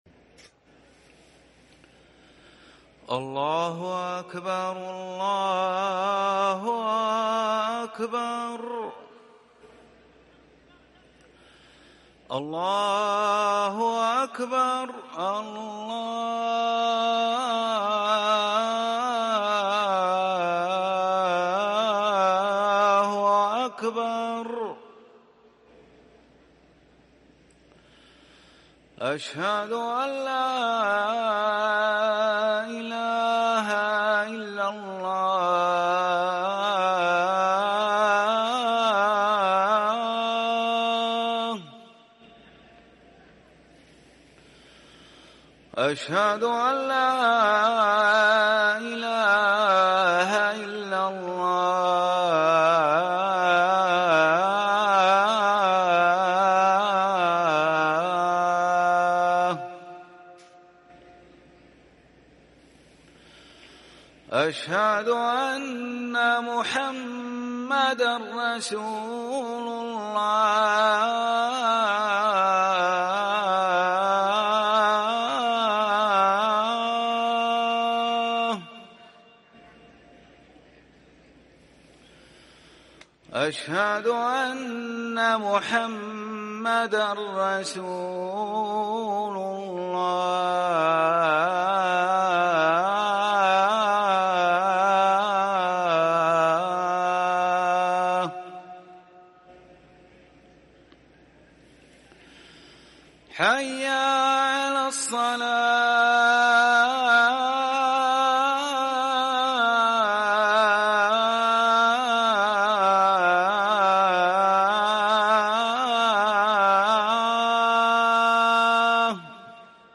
أذان العصر للمؤذن ماجد العباس الثلاثاء 10 صفر 1444هـ > ١٤٤٤ 🕋 > ركن الأذان 🕋 > المزيد - تلاوات الحرمين